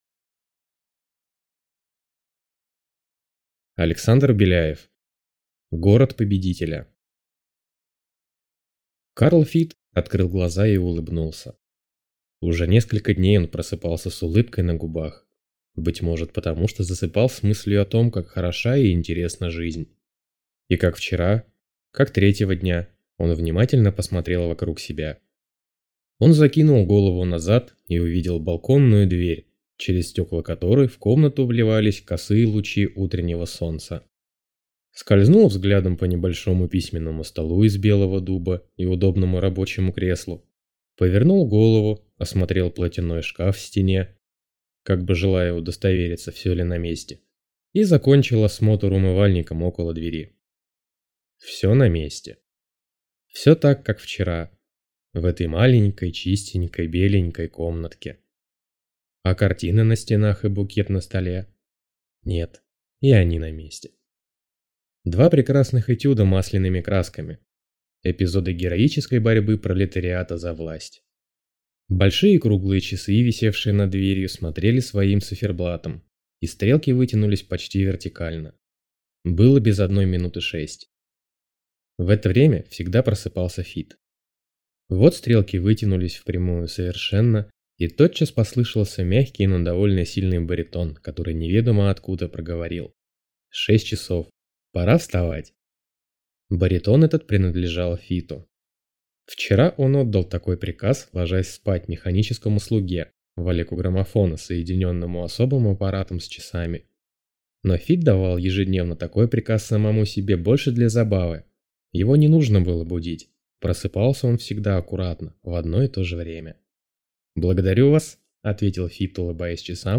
Аудиокнига Город победителя | Библиотека аудиокниг